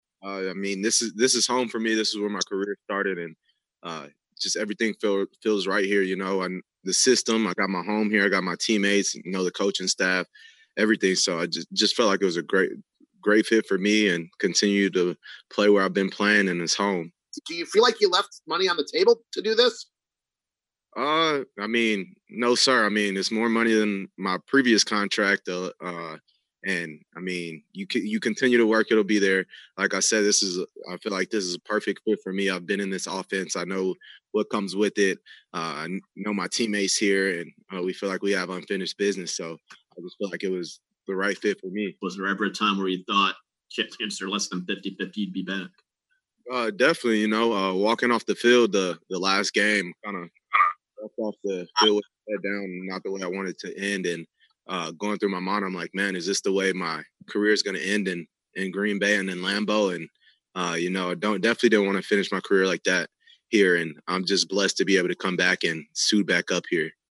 Aaron Jones meets the zoom media Friday.